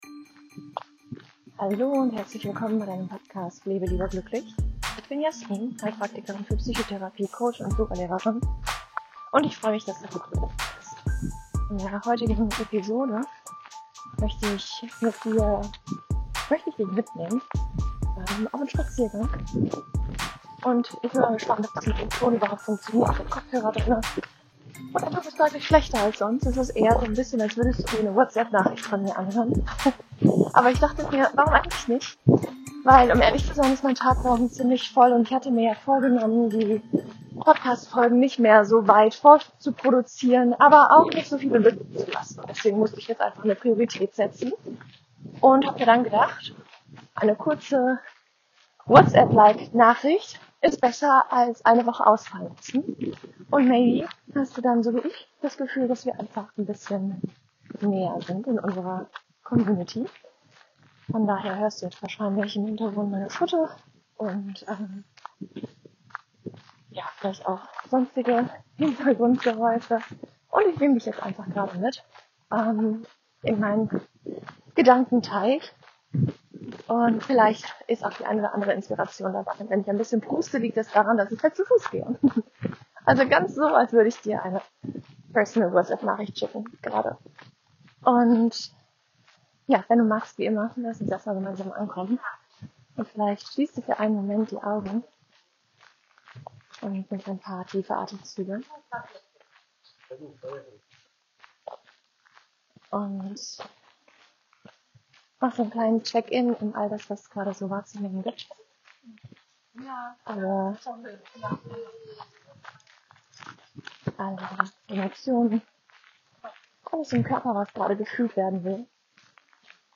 Diese Folge ist wie eine Sprachnachricht an eine Freundin – spontan, echt und von Herzen.